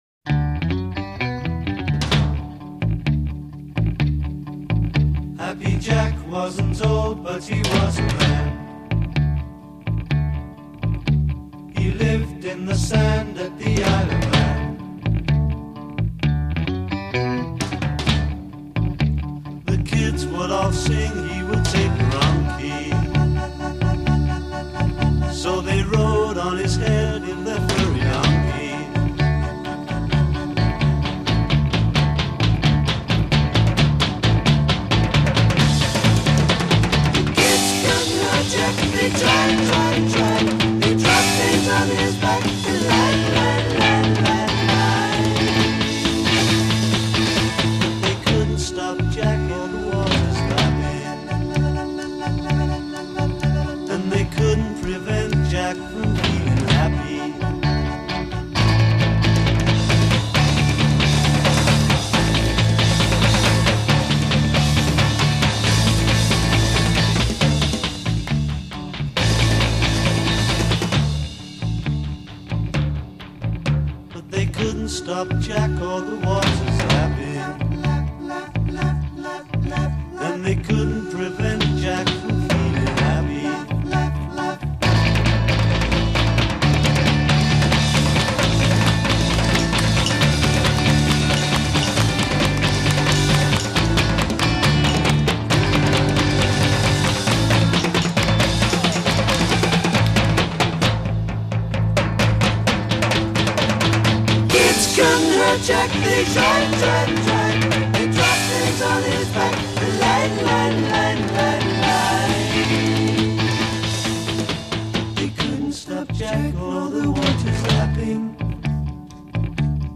CBS Studios, London: vocal tracks & mixing, 10 November 1966
Ritornel 0:00 4 guitar, bass, and drums play theme in unison
Add extending measures to build tension.   b
B Chorus : 24 Two-part harmony. c
Flourish : 36 Drums as lead instrument.